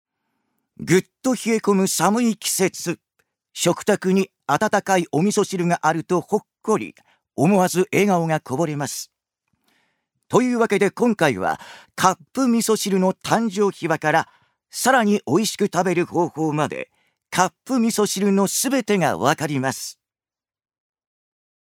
ジュニア：男性
ナレーション１